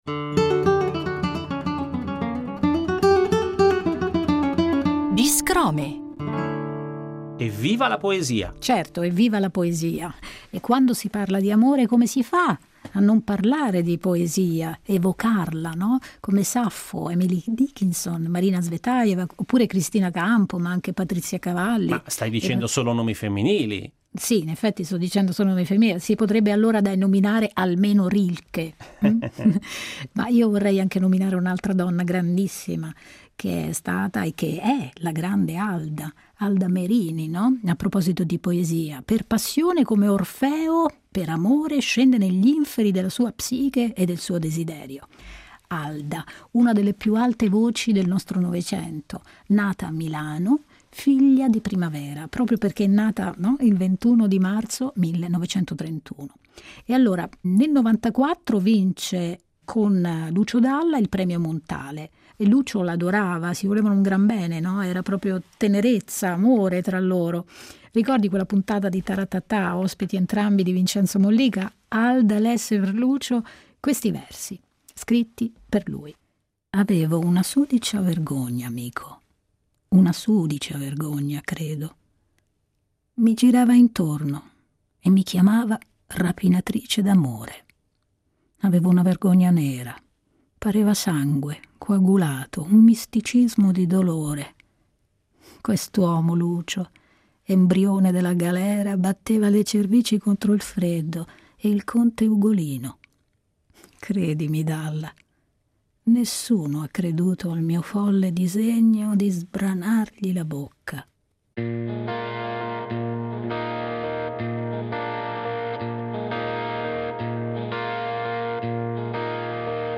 chitarrista
porta in scena nel metodo dello spoken word, storie e racconti dei nostri tempi sotto forma di versi, una poesia orale nel battito della musica. Il viaggio racconta storie di un'umanità fragile e forte, innamorata e perduta, che riflette sul valore che può libertà.